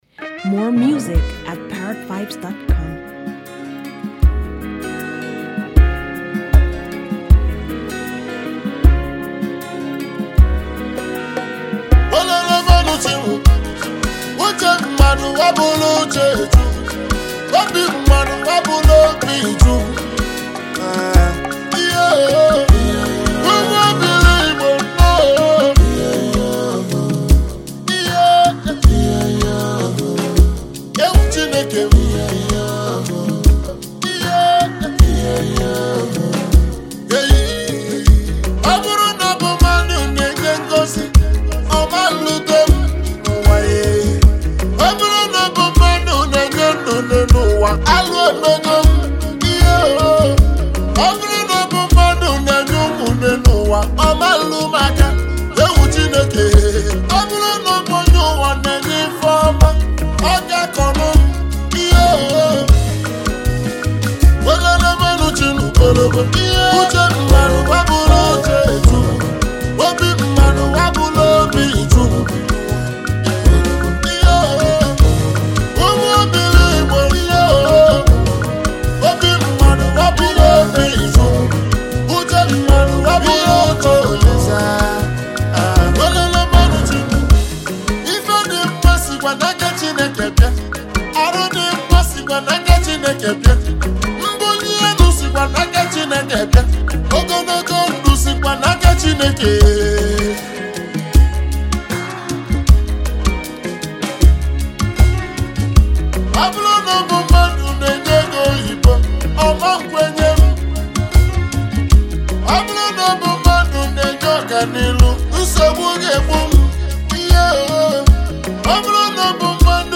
Noteworthy Nigerian highlife music duo